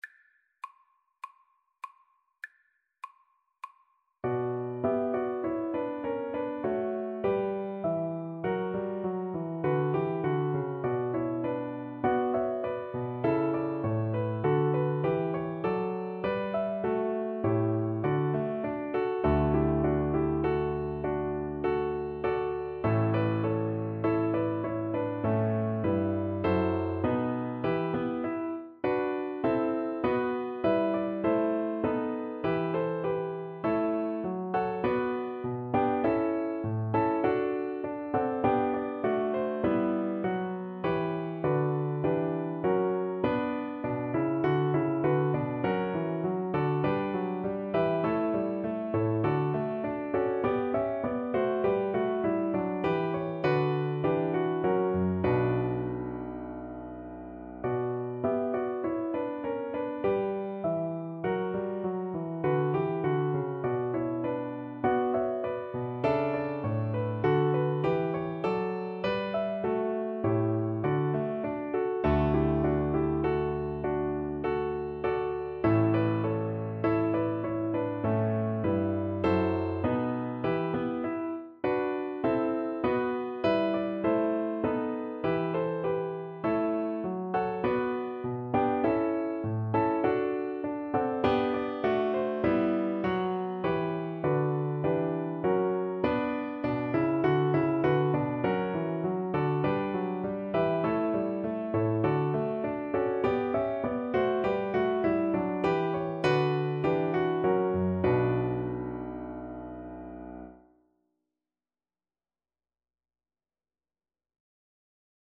Play (or use space bar on your keyboard) Pause Music Playalong - Piano Accompaniment Playalong Band Accompaniment not yet available reset tempo print settings full screen
B minor (Sounding Pitch) (View more B minor Music for Viola )
Classical (View more Classical Viola Music)